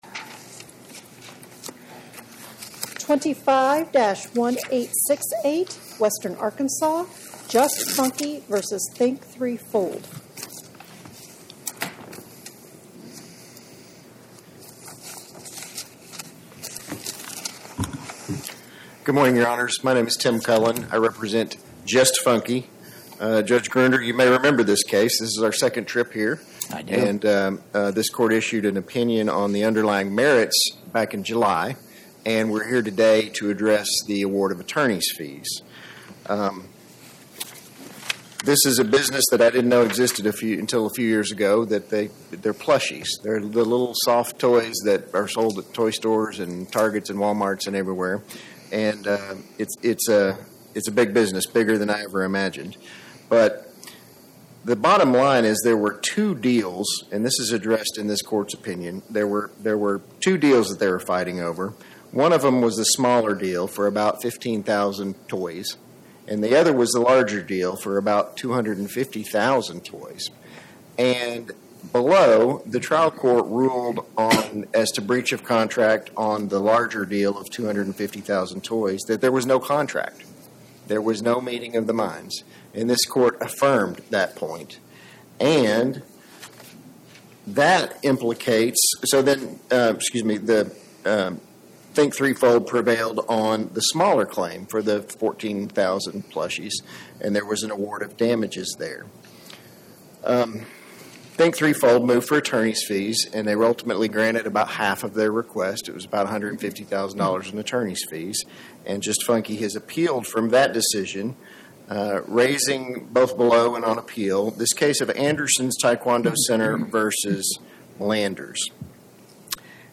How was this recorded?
My Sentiment & Notes 25-1868: Just Funky, LLC vs Think 3 Fold, LLC Podcast: Oral Arguments from the Eighth Circuit U.S. Court of Appeals Published On: Wed Dec 17 2025 Description: Oral argument argued before the Eighth Circuit U.S. Court of Appeals on or about 12/17/2025